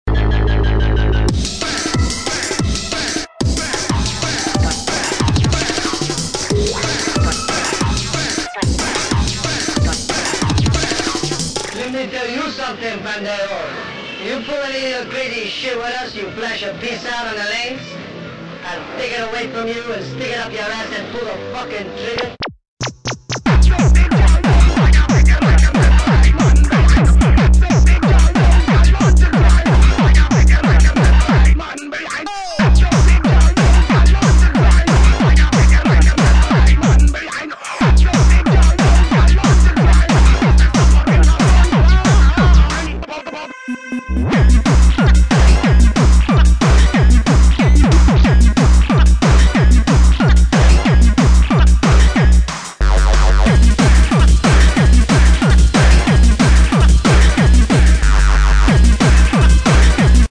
Pumping  Hardtek
du pur son PUMPING-RAGGA.
ultra dancefloor.
Grosse vibe 100% made in CANADA.